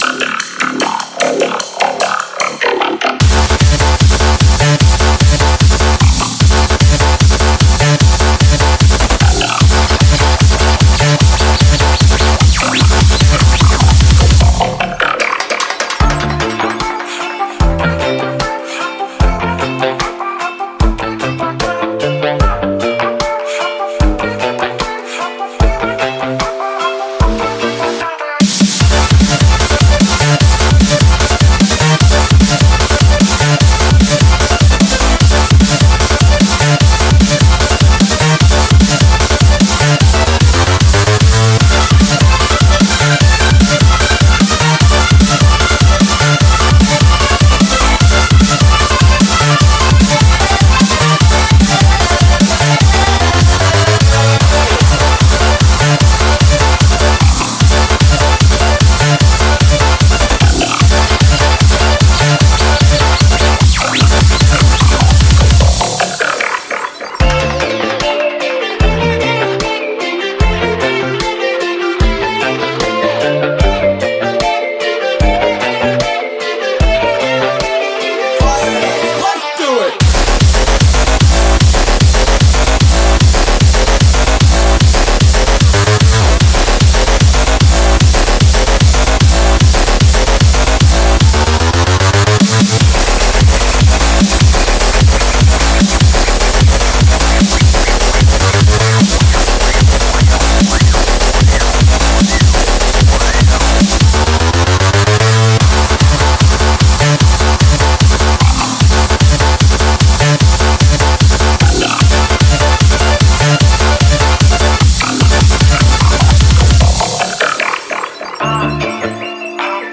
BPM38-300
Audio QualityPerfect (Low Quality)